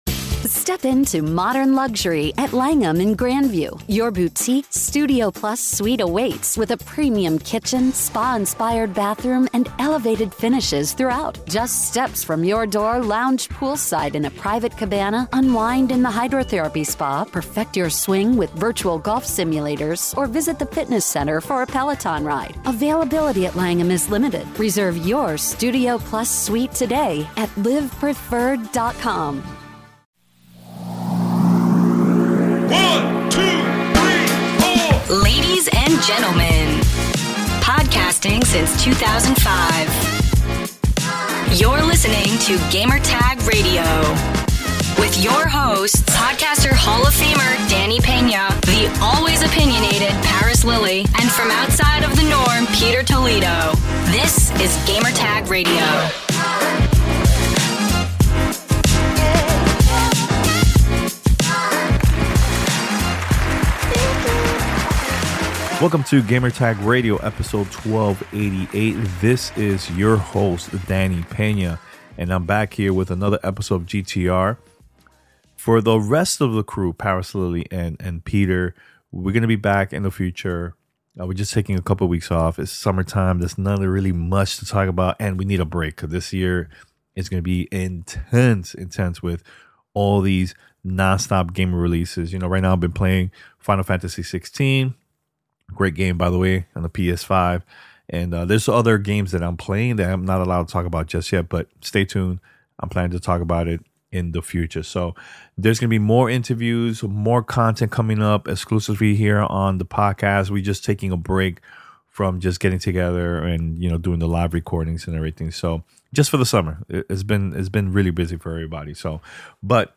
This week on Gamertag Radio: Assassin's Creed Mirage and A Highland Song Interviews. Sonic Superstars hands-on impressions recorded during Summer Game Fest 2023 (unreleased).